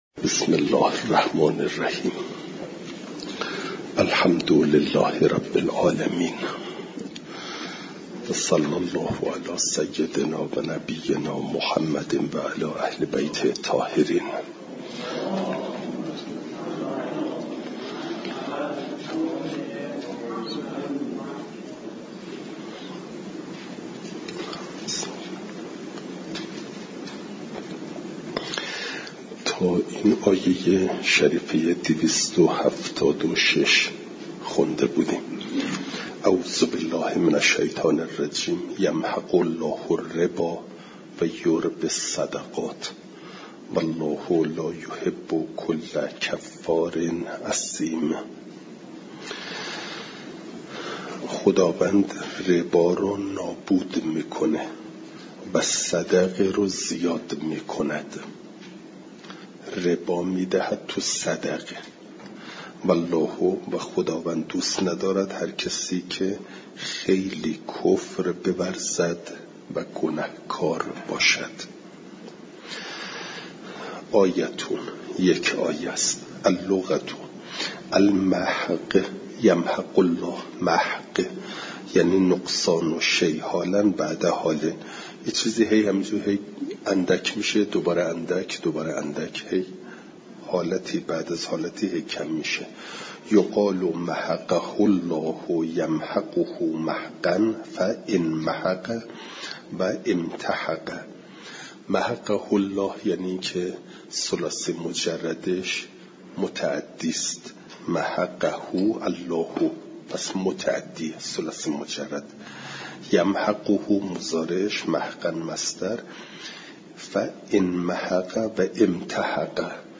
فایل صوتی جلسه دویست و چهل و ششم درس تفسیر مجمع البیان